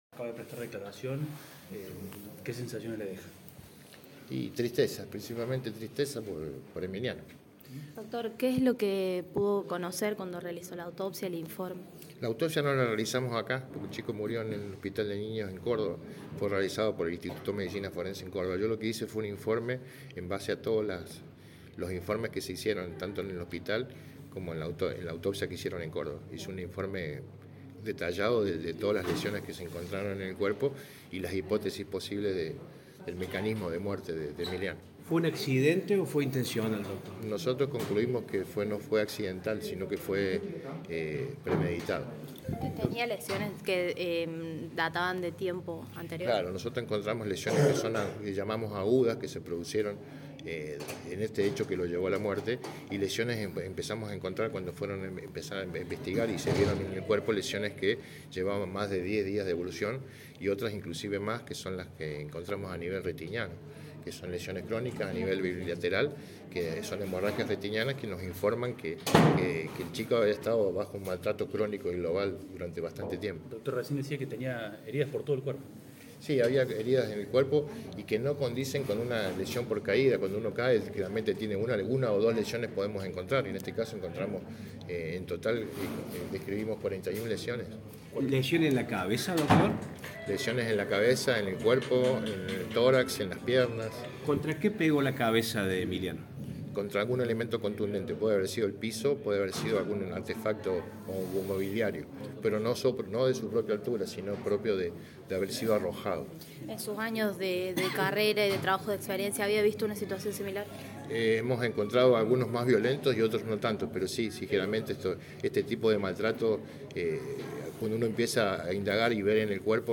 En una entrevista a los medios de comunicación explicó detalles de lo que expuso en la audiencia.